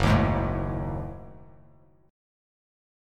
F#m6add9 chord